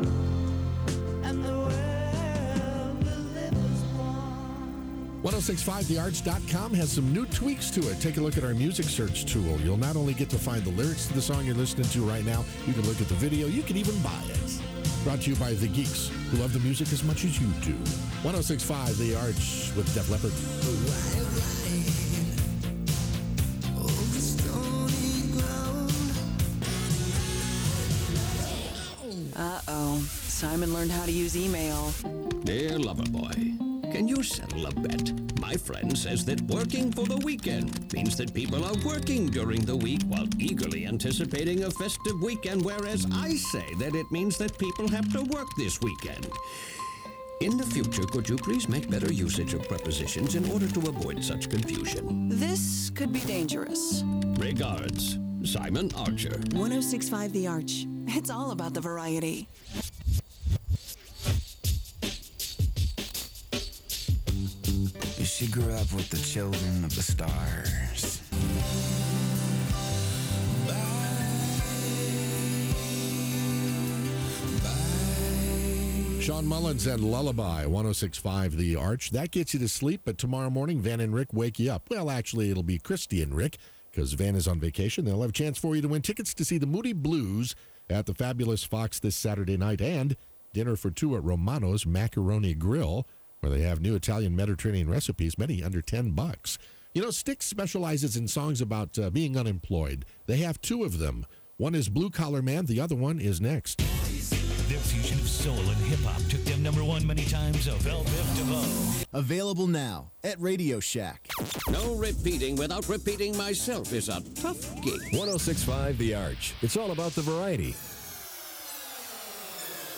WARH Unknown announcer aircheck · St. Louis Media History Archive